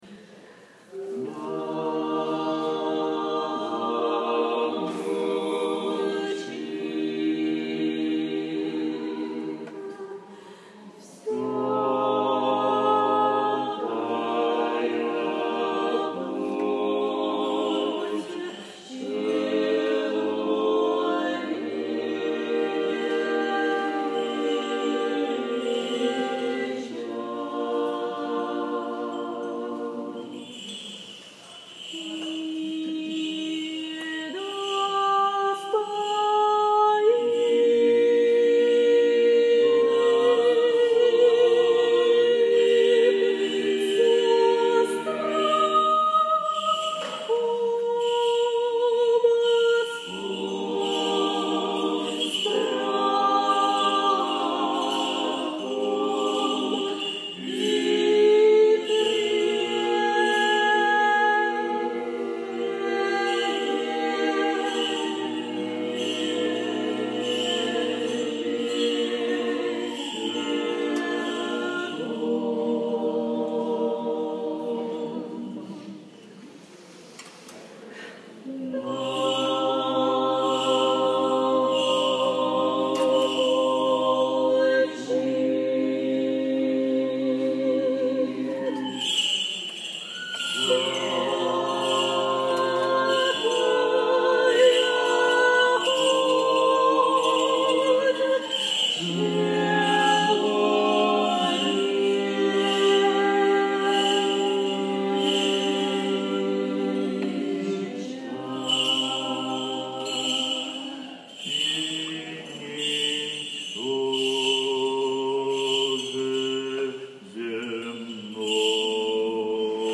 Духовные песнопения : Акция «Пасхальная весть» в Середниково
27 апреля, в Алексиевском храме д. Середниково на Божественной Литургии, посвященной воспоминанию Субботнего дня упокоения Господа перед Его Воскресением, прихожане с благоговением помолились перед Плащаницей. Прозвучали умилительные песнопения этого дня в исполнении клиросного хора храма.